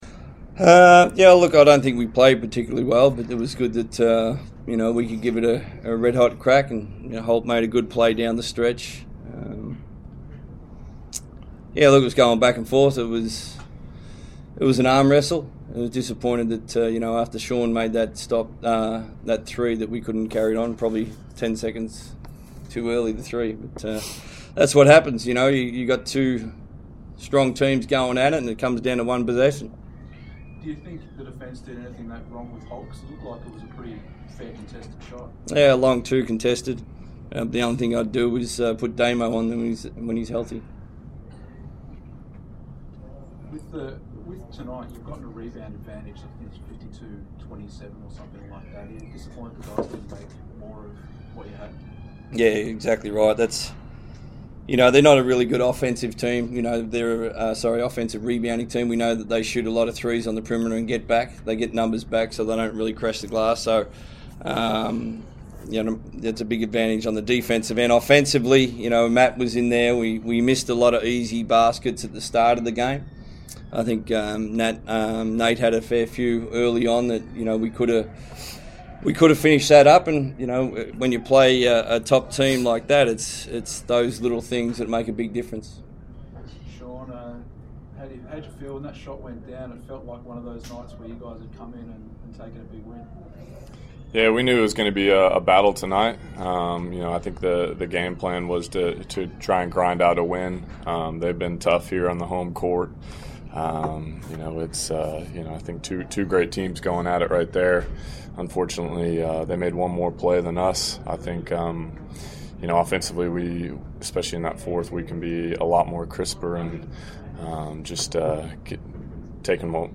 Shawn Redhage and Trevor Gleeson speak to the media after falling to Melbourne United by two points.